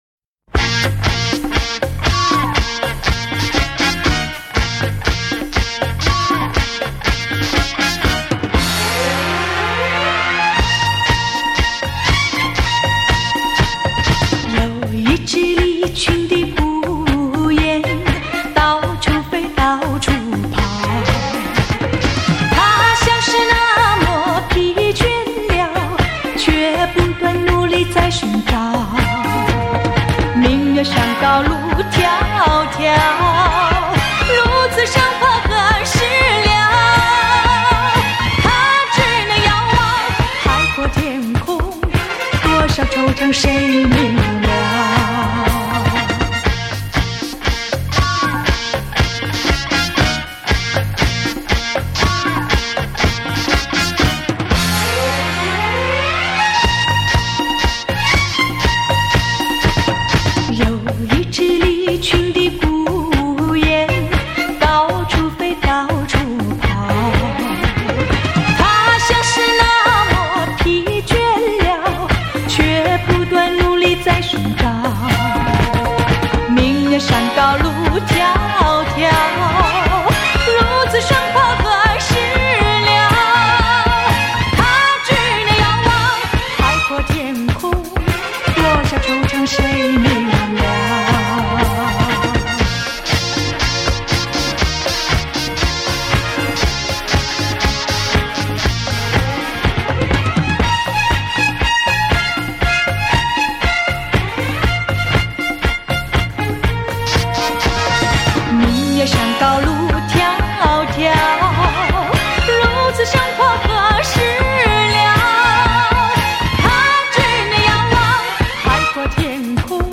聆觅丽影君情歌华倩韵 黑胶唱片原音回放
首度以高保真CD正式出版 原始母带经高新科技原音处理
既保留了黑胶唱片的暖和柔美 也展现了数码唱片的精确清晰
让那甜蜜柔美的声音再度飘进你的心窝 萦牵你的心弦